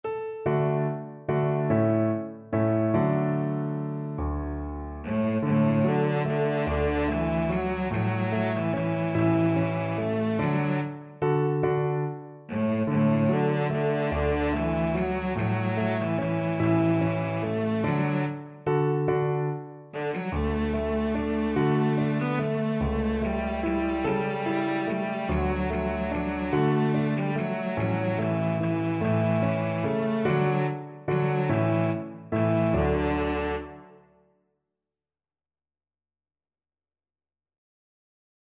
Traditional Trad. Es klappert die Muhle am rauschenden Bach Cello version
3/4 (View more 3/4 Music)
D major (Sounding Pitch) (View more D major Music for Cello )
One in a bar =c.145
Traditional (View more Traditional Cello Music)